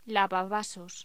Locución: Lavavasos